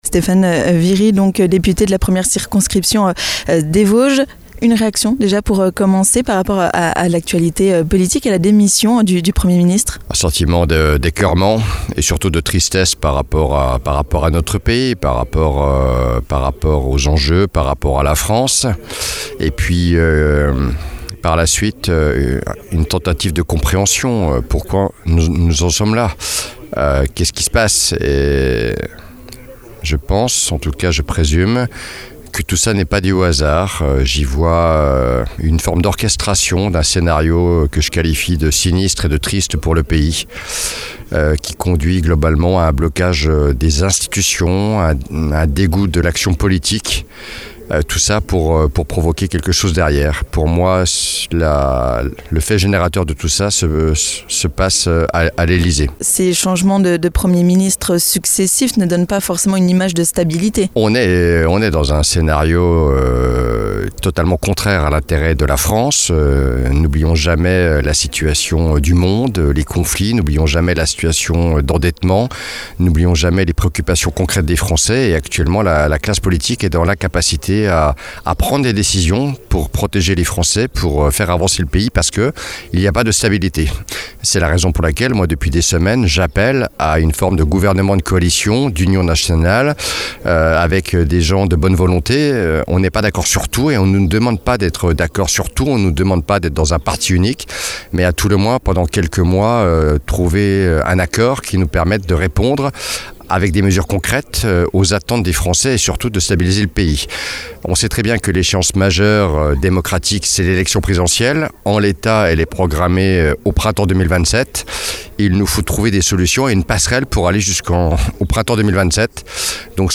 Entre possibilité d'une dissolution de l'Assemblée nationale, un gouvernement de cohabitation ou bien démission du Président, les questions fusent. On en parle avec le député de la première circonscription des Vosges, Stéphane Viry.